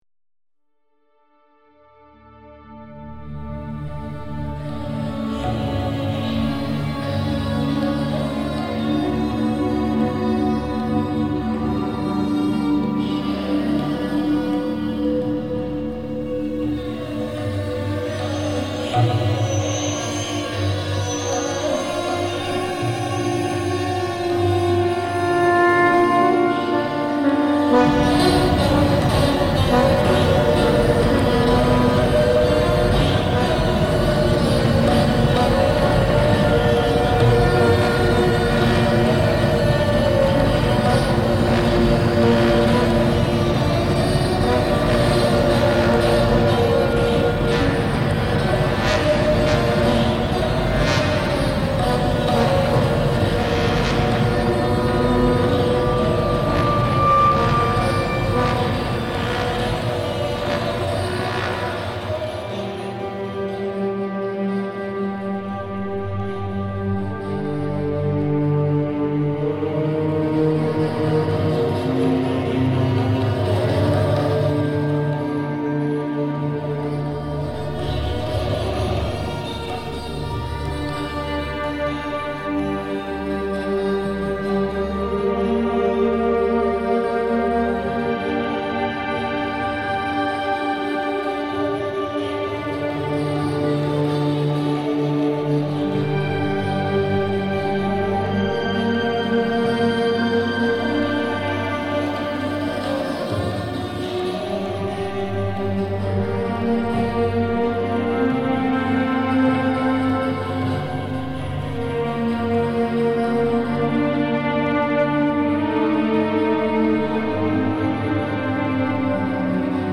I composed this piece in an attempt to understand trance. I sought to merge specific leitmotifs and elements,embedding them within a classical orchestral context.
At eight distinct moments, sliding sounds of bowed strings emerge, followed like a shadow by a frenzied percussion that articulates an almost inhuman cadence.